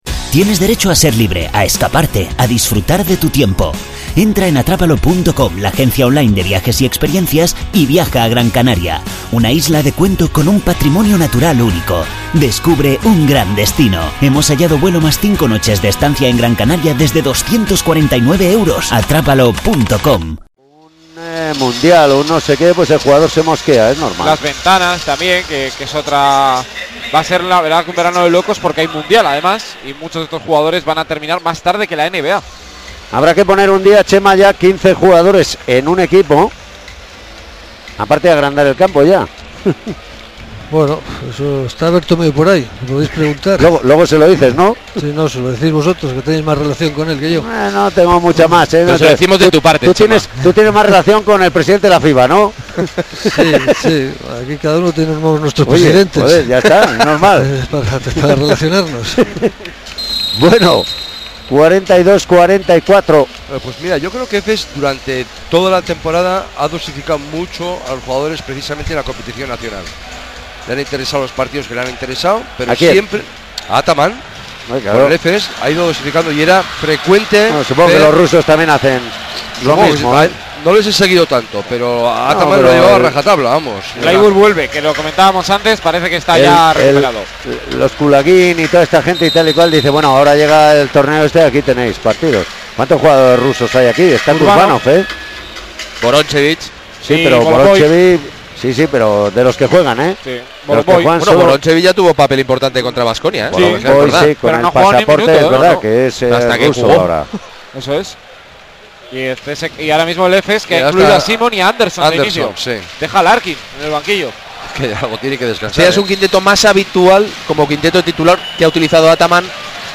CSKA Moscu-Anadolu Efes partido final euroleague 2018-19 retransmisión Radio Vitoria